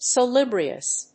発音記号
• / səlúːbriəs(米国英語)
salubrious.mp3